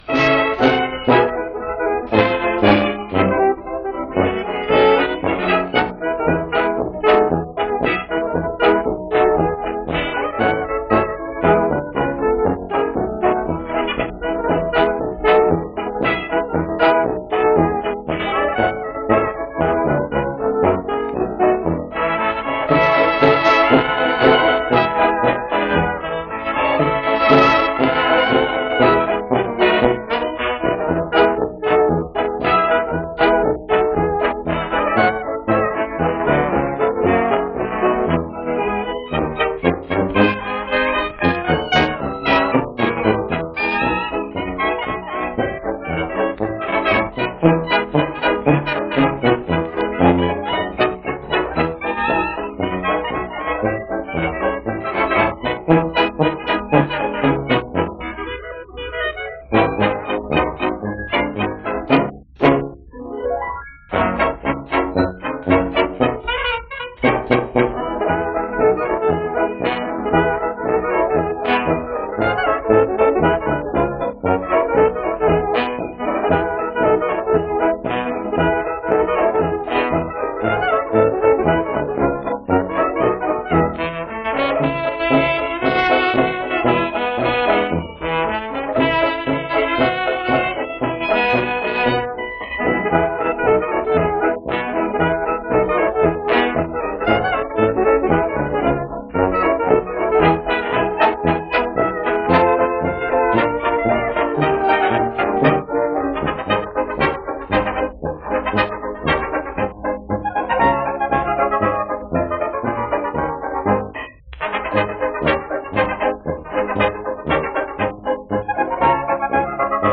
Two highly danceable tunes from 1925.